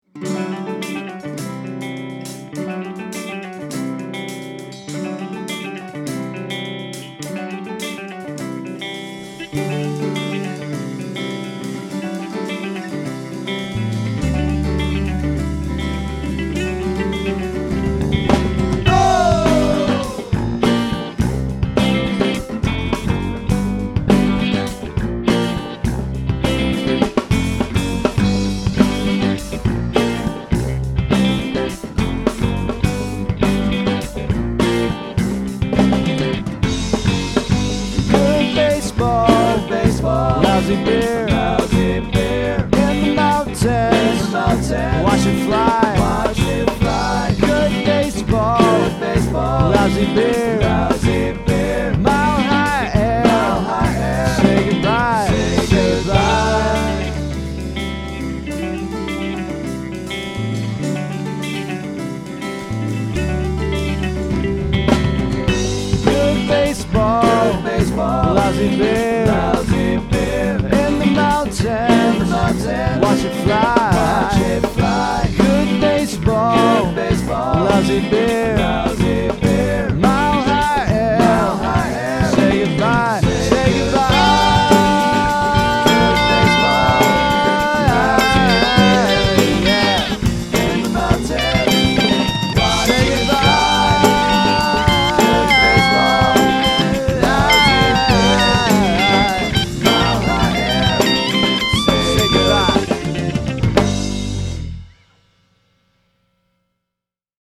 After a little more research, I discovered that Denver is considered a hotbed for the jam band scene.
Little unison licks, the first piano part in this project, a solid groove, a guitar solo, and the “David Bowie” (The Phish tune, not the guy) / “Dinner and a Movie” style of lyric writing.